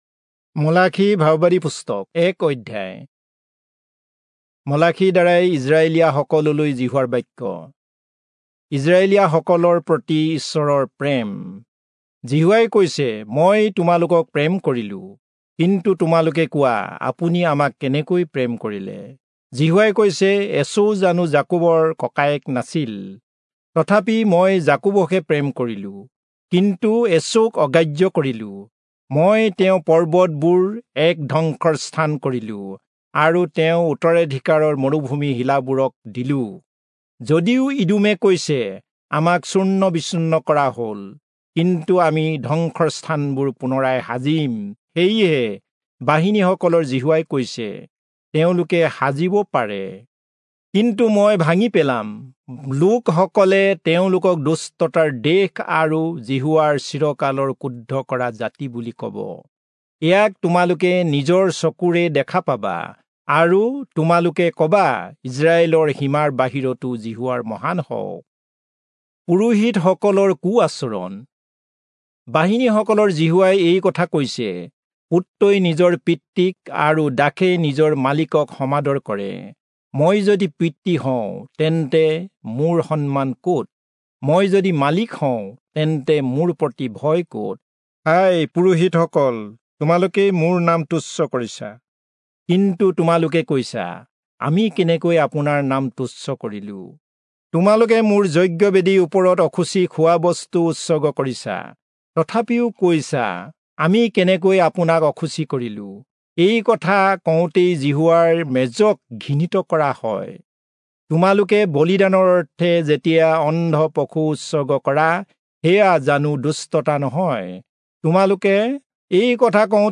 Assamese Audio Bible - Malachi All in Rcta bible version